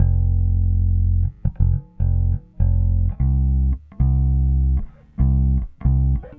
Weathered Bass 07.wav